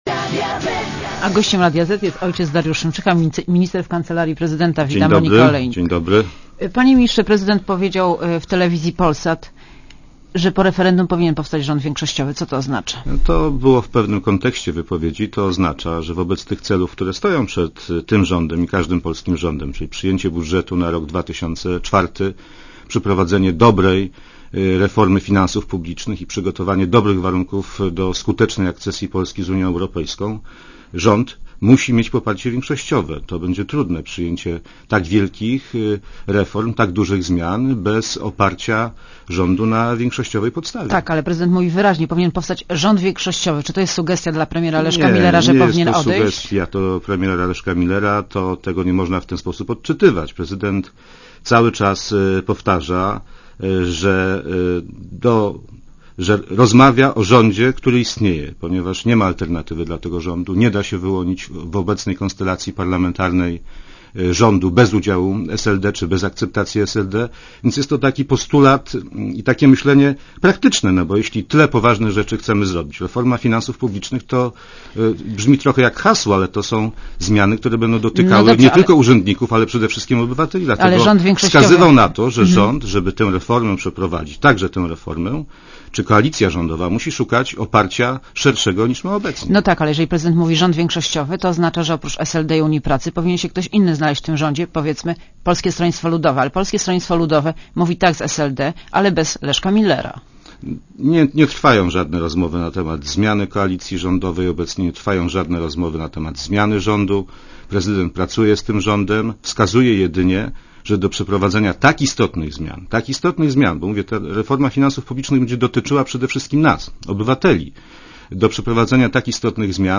© (RadioZet) Posłuchaj wywiadu (2,4 MB) Panie Ministrze, prezydent powiedział w Telewizji Polsat, że po referendum powinien powstać rząd większościowy.